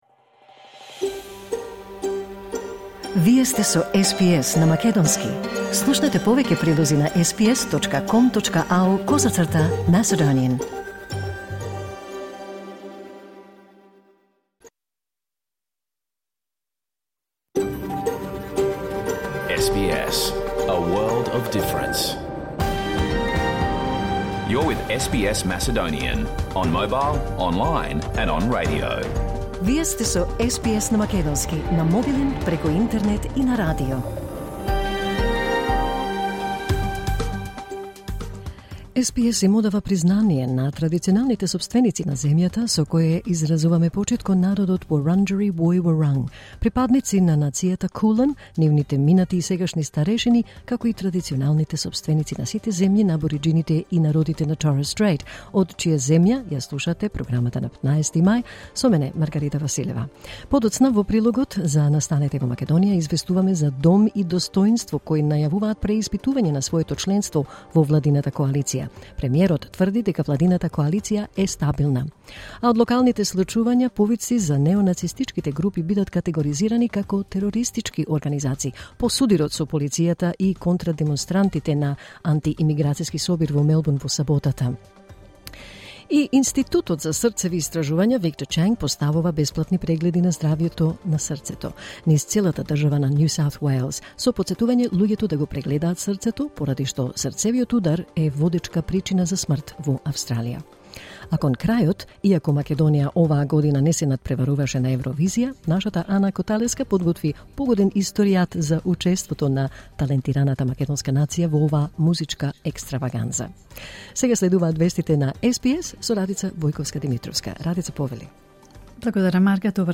SBS Macedonian Program Live on Air 15 May 2021